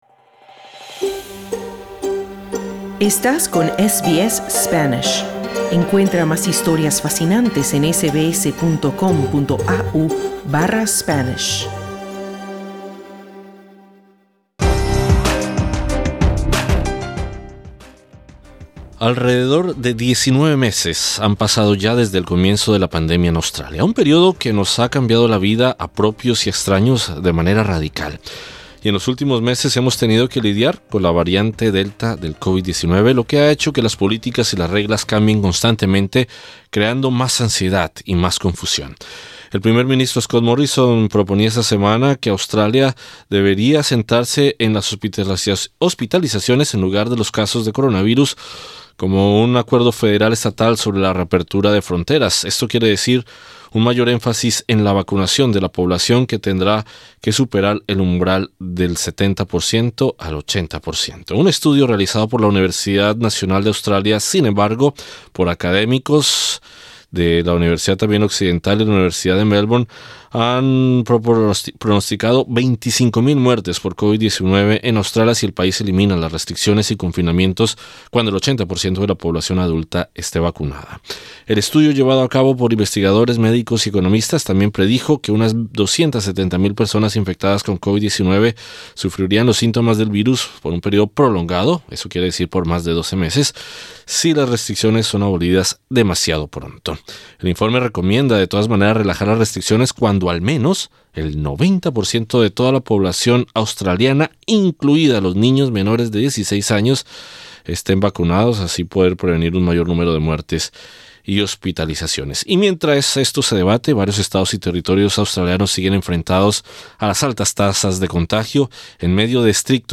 En entrevista con SBS Spanish, la colombiana radicada en Australia advierte que uno de los mayores impactos de los confinamientos ha sido el completo aislamiento de los adultos mayores, que ha borrado sus recuerdos.